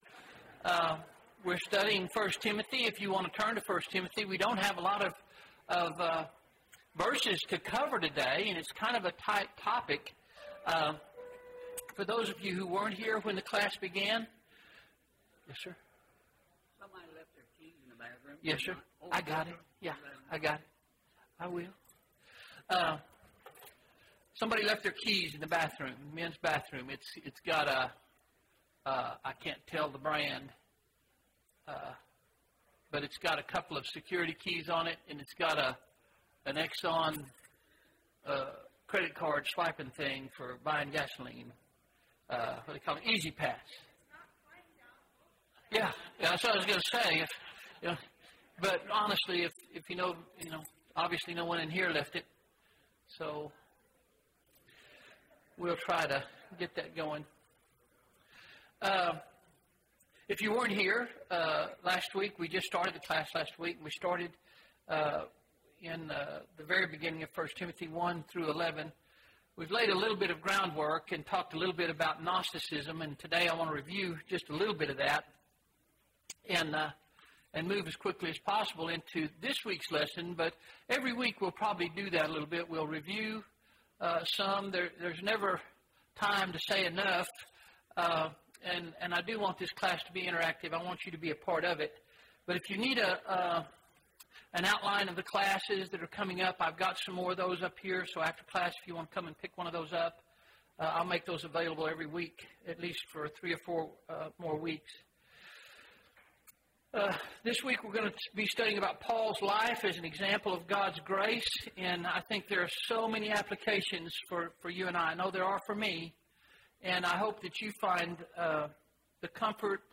A Study of the Book of 1 Timothy (2 of 12) – Bible Lesson Recording